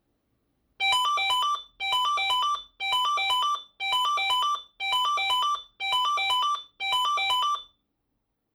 Melder3.wav